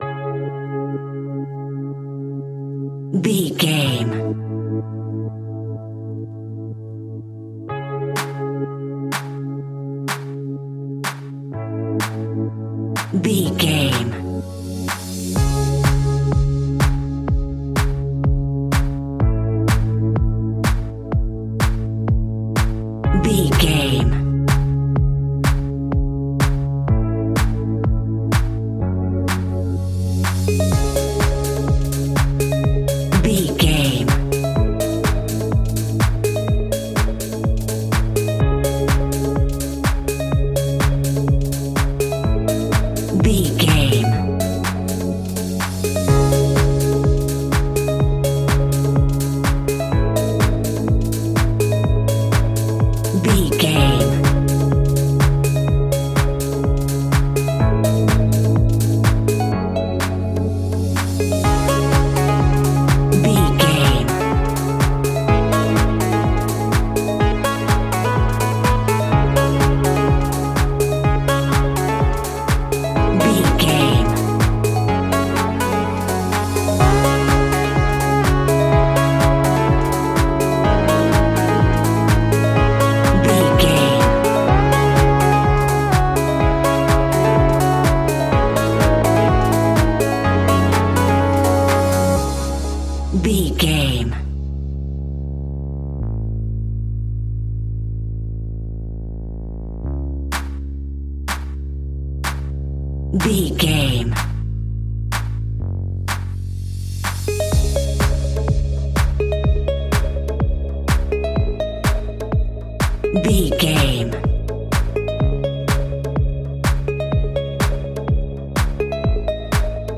Aeolian/Minor
C#
groovy
futuristic
hypnotic
dreamy
smooth
synthesiser
drum machine
electric guitar
funky house
deep house
nu disco
upbeat
wah clavinet
synth bass